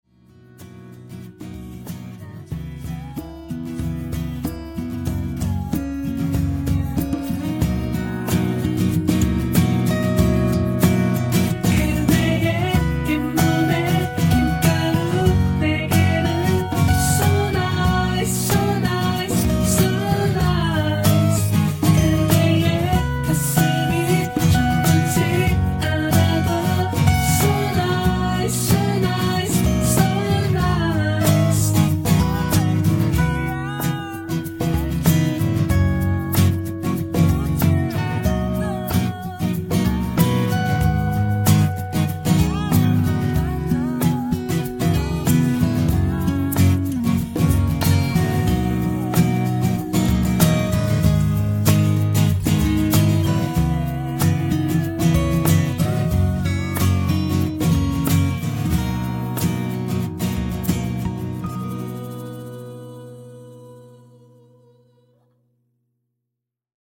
음정 원키 3:29
장르 가요 구분 Voice MR